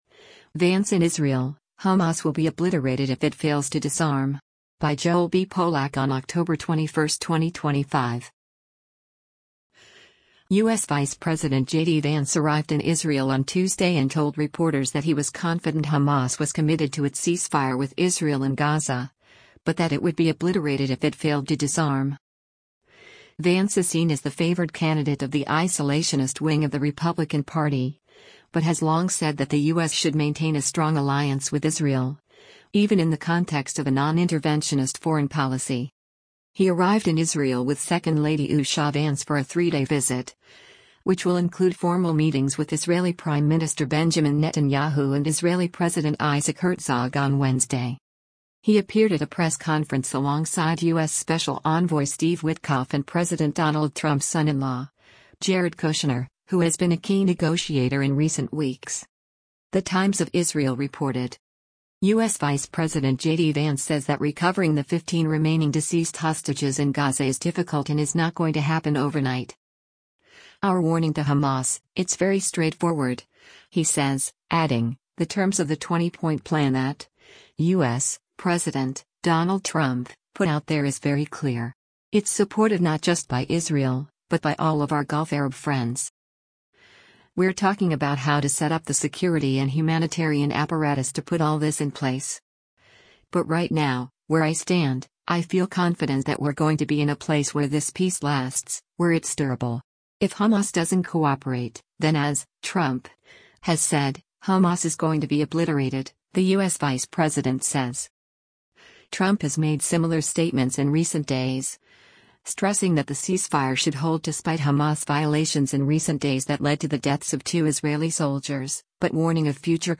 U.S. Vice President JD Vance speaks during a press conference following a military briefin